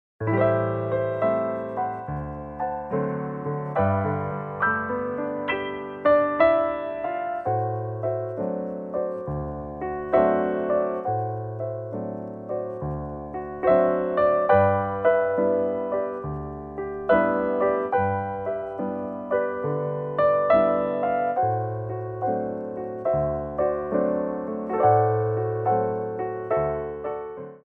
Piano Arrangements
SLOW TEMPO